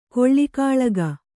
♪ koḷḷi kāḷaga